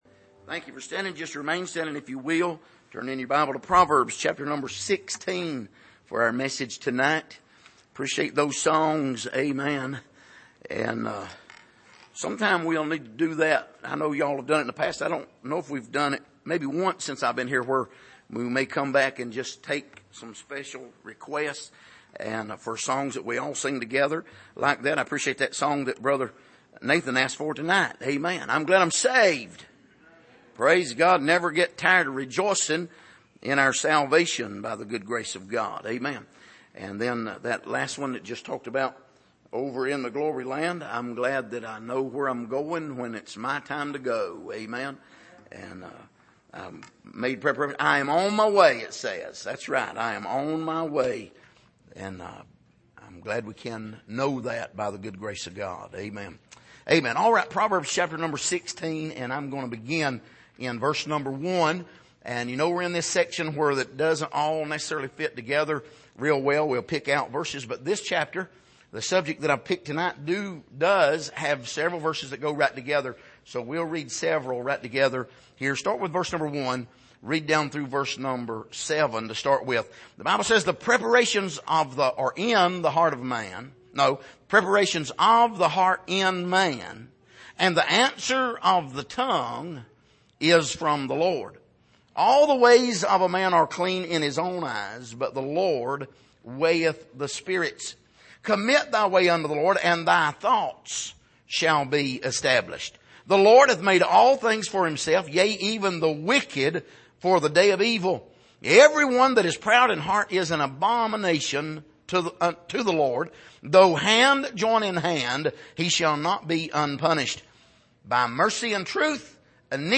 Passage: Proverbs 16:1-7,9-15,38 Service: Sunday Evening